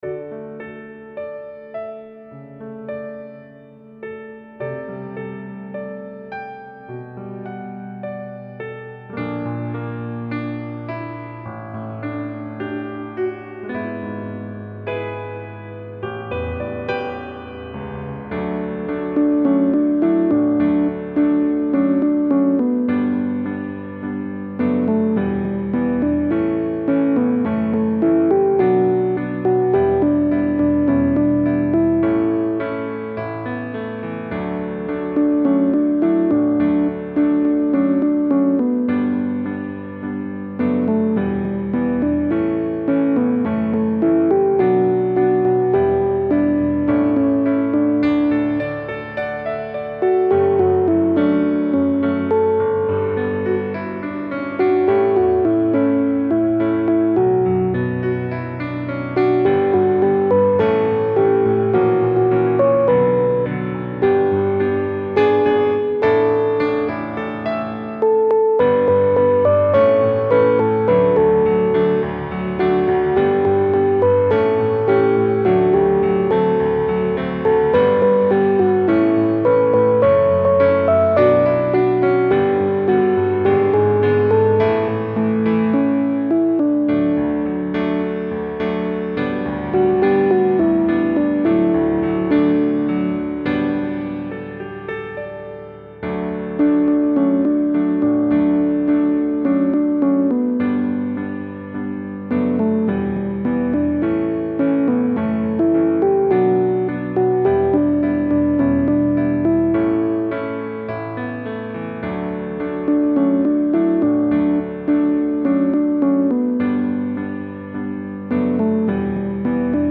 カラオケ音源